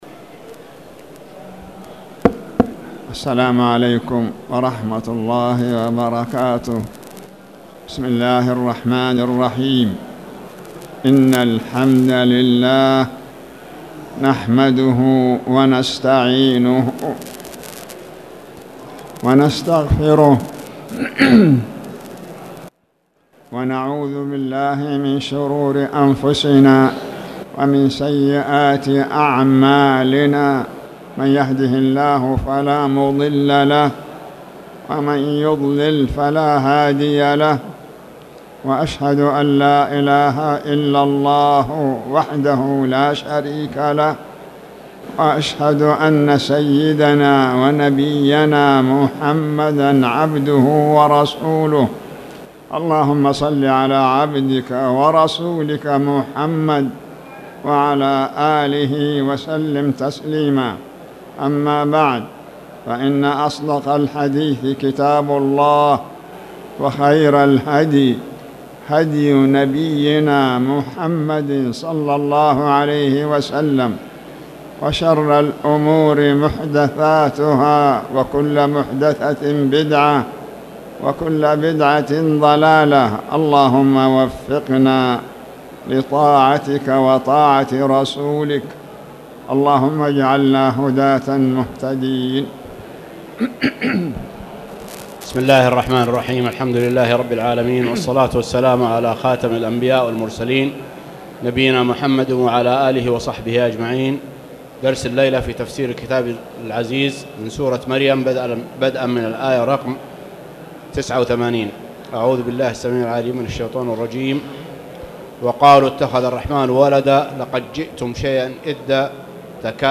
تاريخ النشر ١١ جمادى الأولى ١٤٣٨ هـ المكان: المسجد الحرام الشيخ